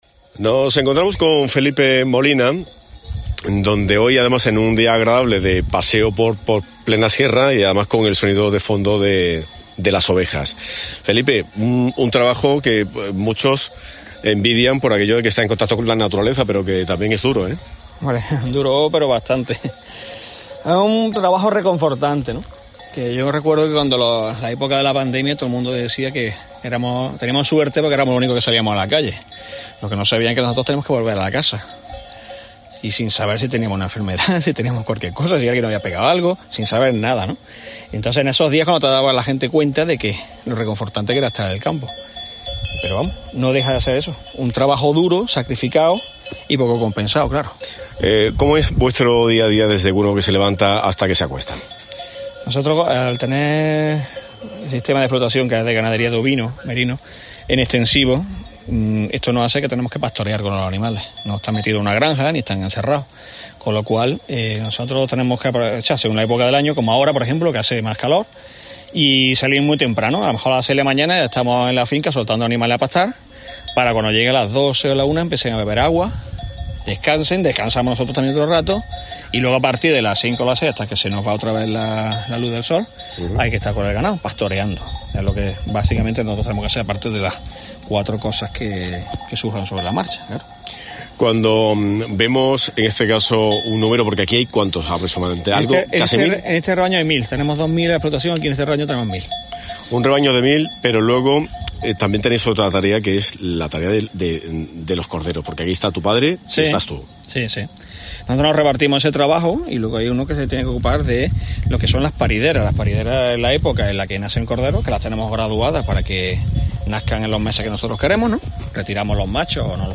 A medida que íbamos caminando comenzamos a escuchar unos cencerros, eso significaba que estábamos cerca del rebaño.